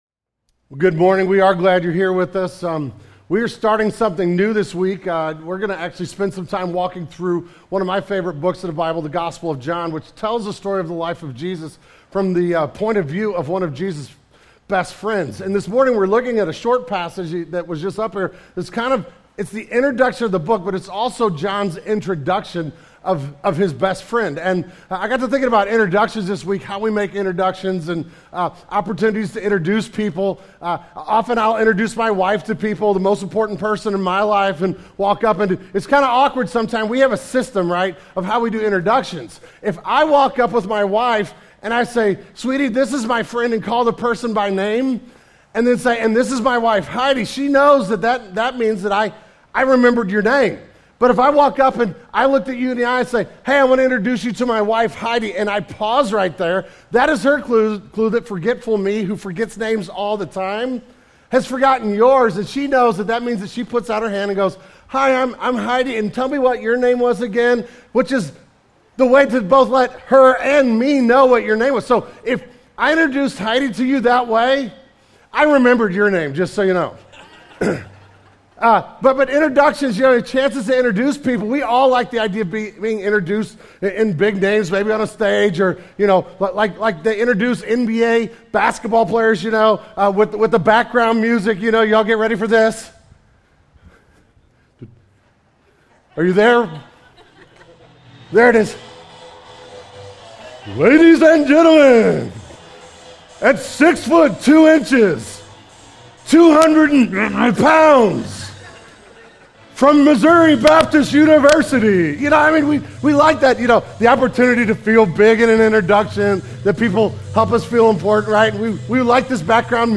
This morning we are introducing a new sermon series.